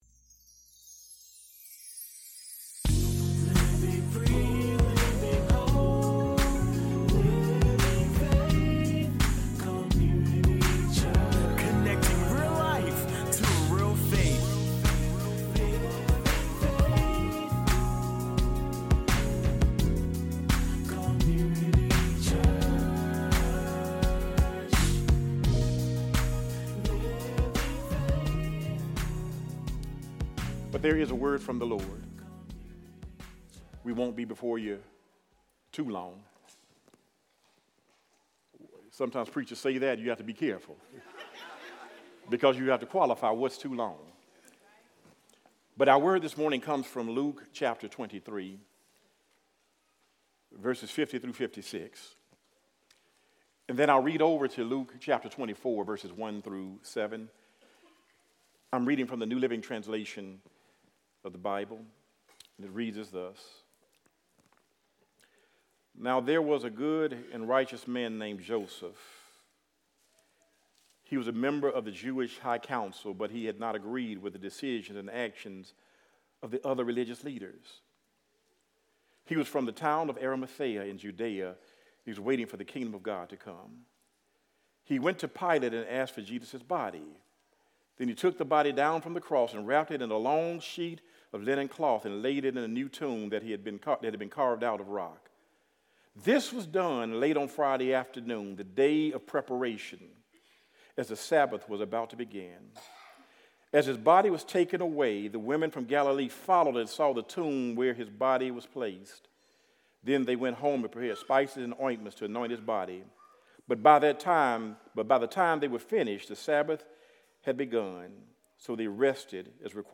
Audio Sermons | Living Faith Community Church